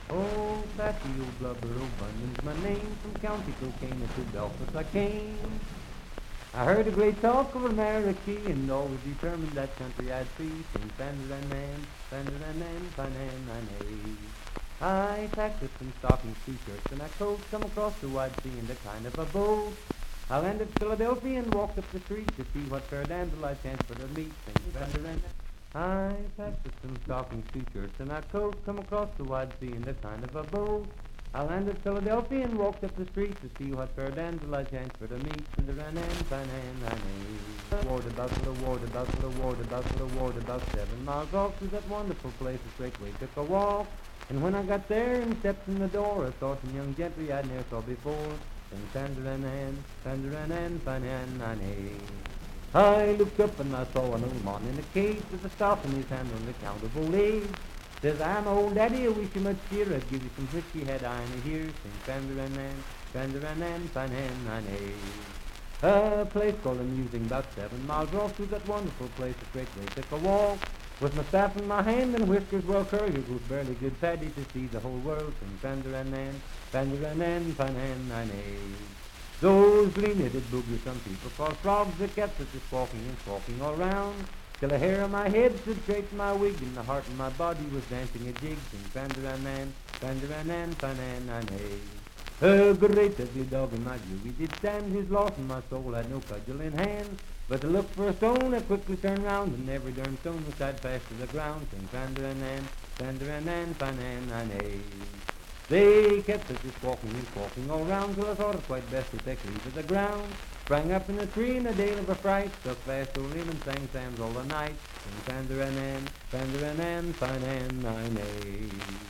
Unaccompanied vocal music
Verse-refrain 8(6w/R).
Ethnic Songs
Voice (sung)